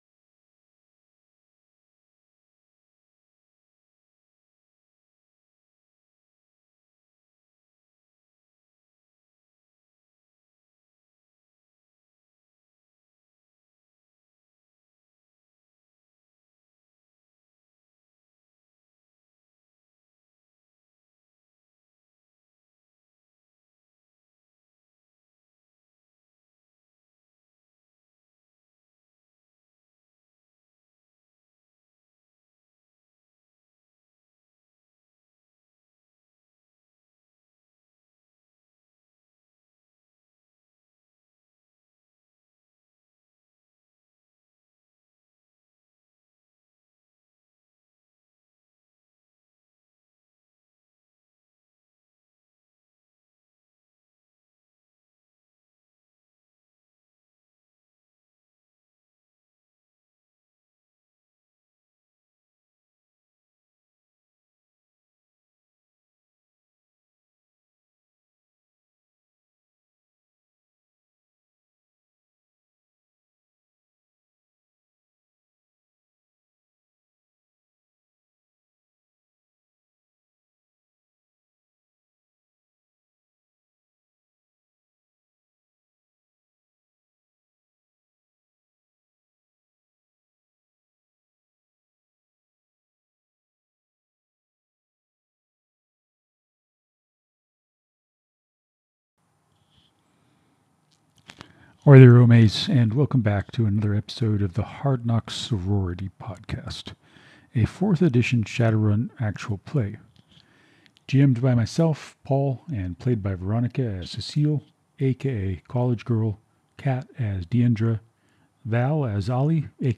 Welcome to the Hard Knocks Sorority PodCast, a Shadowrun 4th Edition Actual Play Podcast.